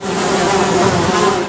flies sound.
flies_1.wav